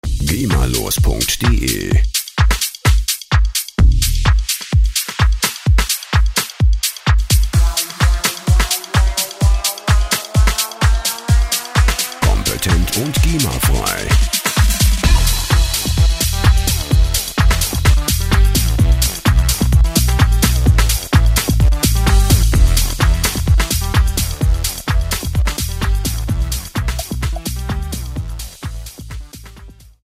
gemafreie Drum Loops
Musikstil: Deep House
Tempo: 128 bpm